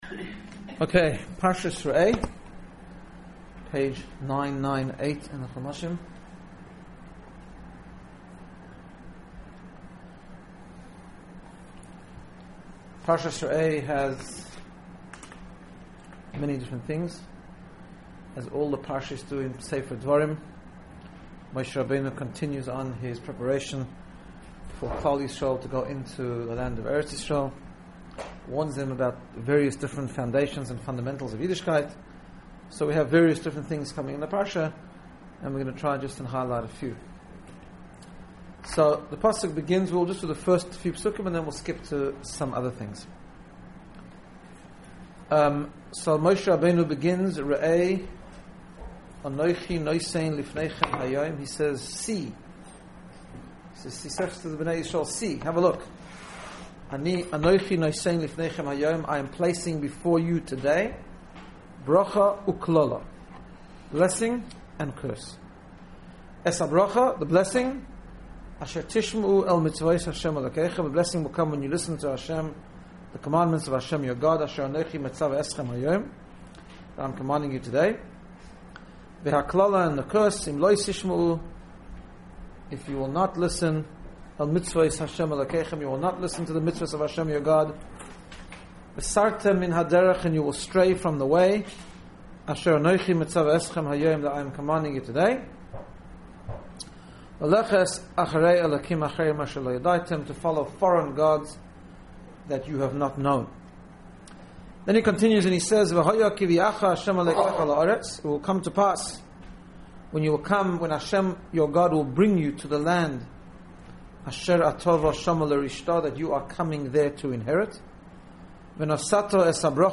Shiur 5770